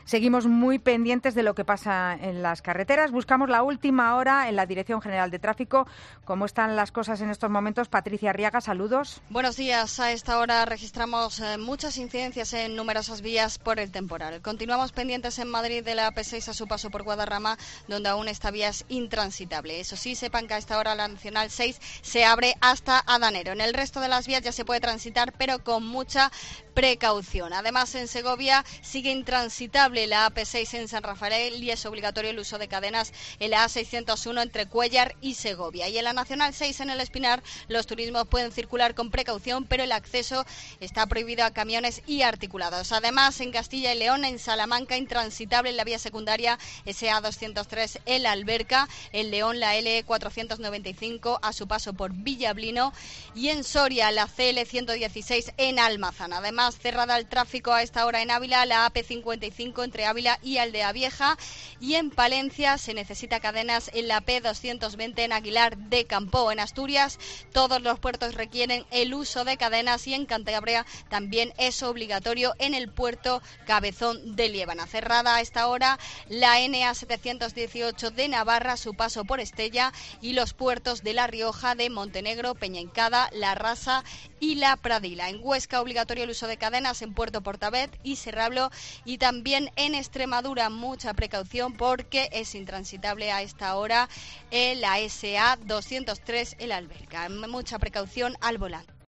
Escucha la última hora sobre el estado de las carreteras en el boletín informativo de las 13.00 horas